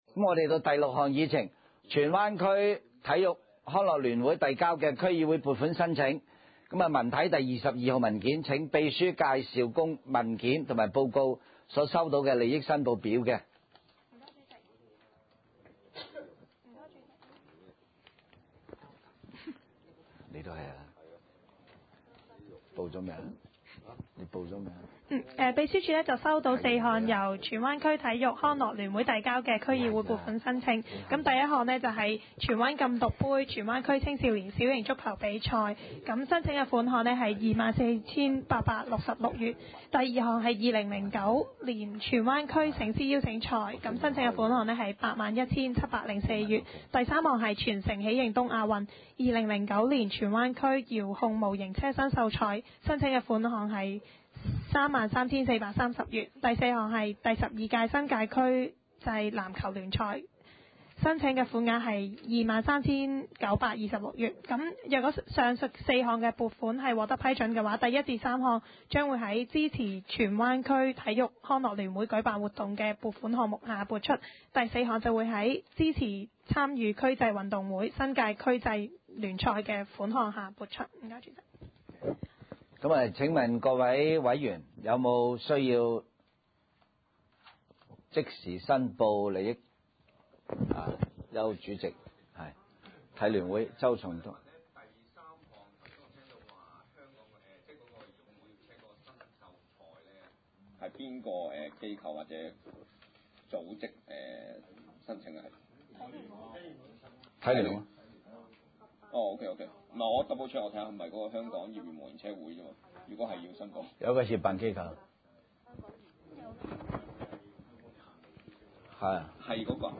文娛康樂及體育委員會第十二次會議
荃灣民政事務處會議廳